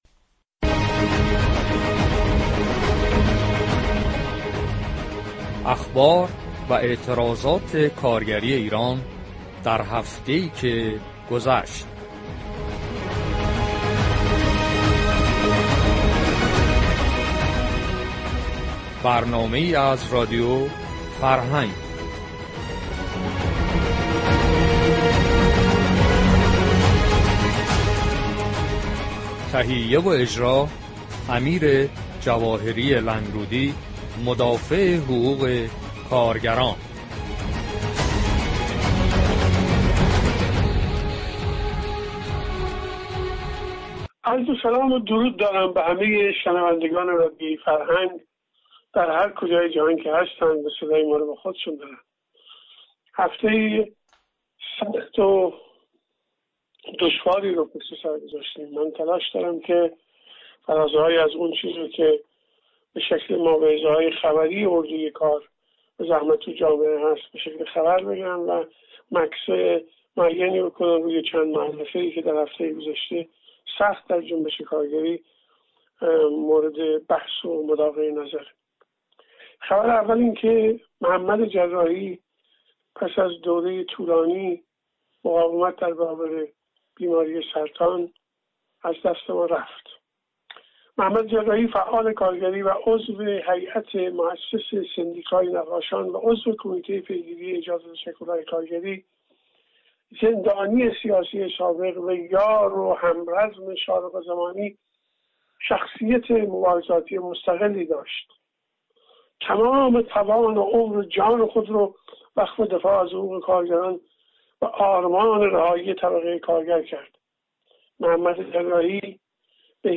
با هم به پای این گفتگو بنشینیم !